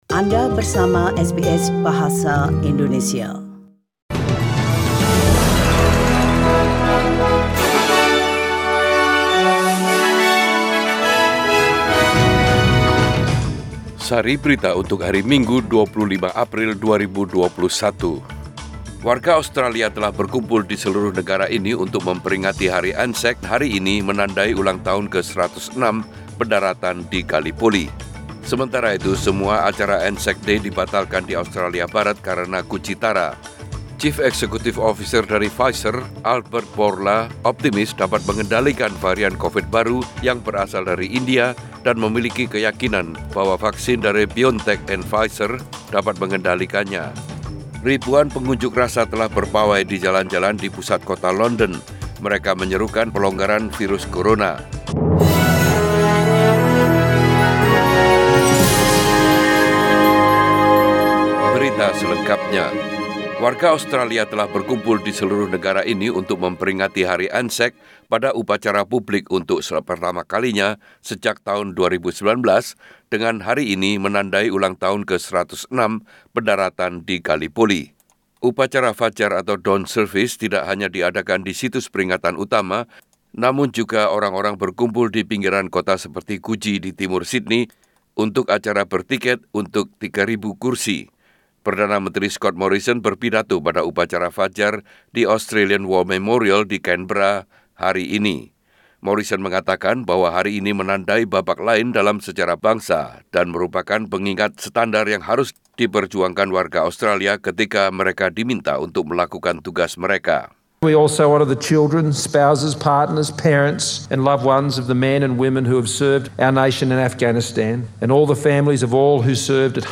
SBS Radio News in Bahasa Indonesia - 25 April 2021
Warta Berita SBS Radio dalam Bahasa Indonesia Source: SBS